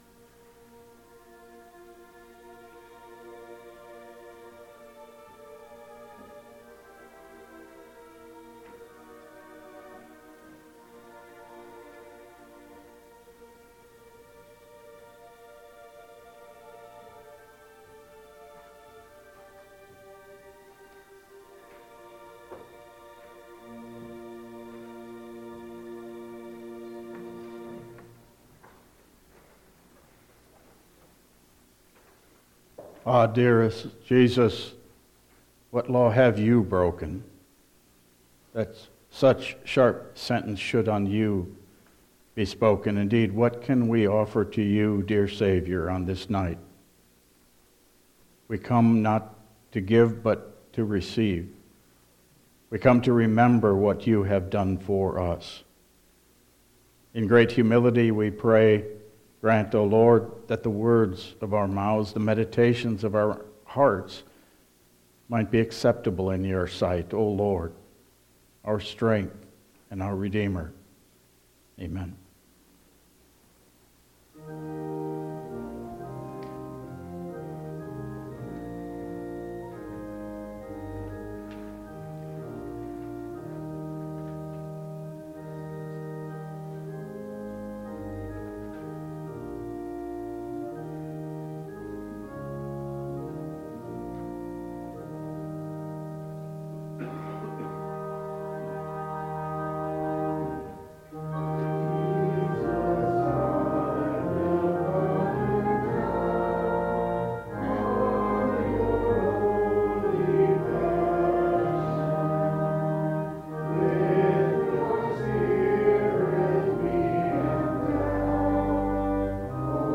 Service Type: Good Friday